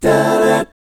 1-DMI7    -R.wav